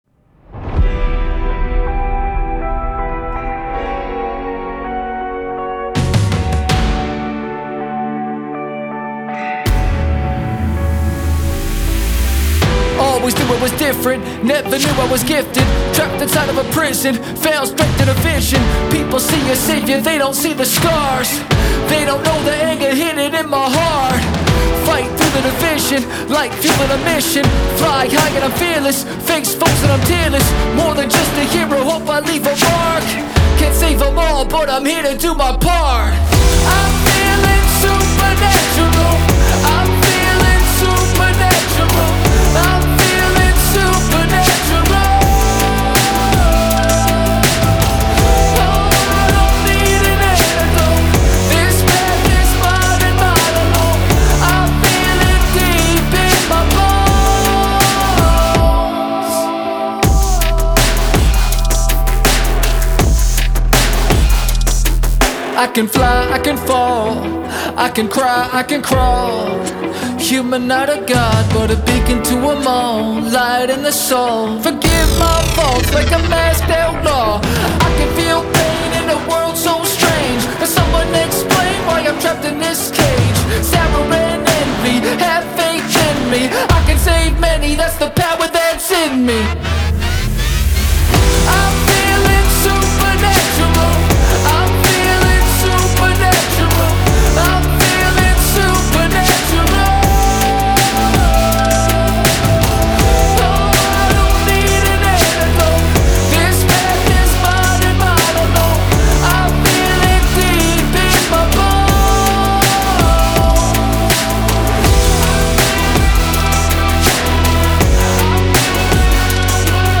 • Жанр: Rap, Alternative